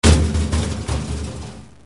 Suono effetto - wav Bidone che cade
Bidone che cade
Rumore di grosso bidone metallico che cade e rotola.